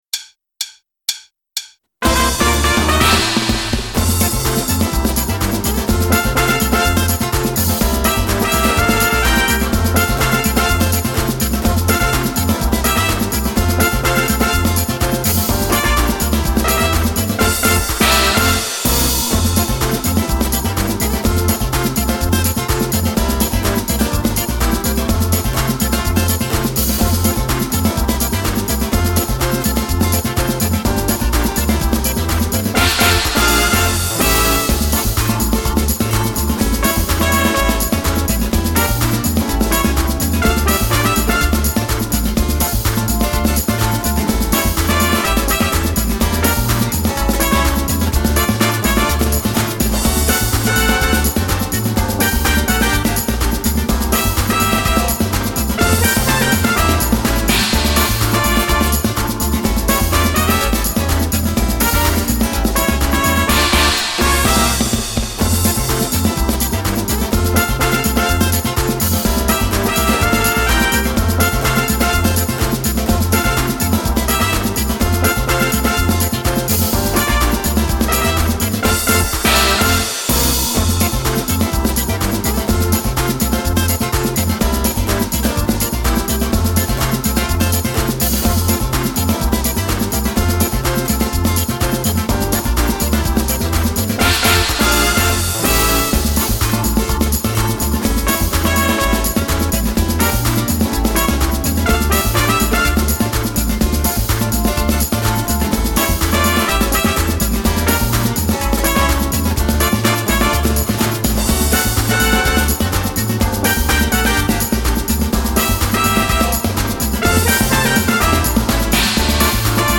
Samba Batucada
Le play- back
pour chanteur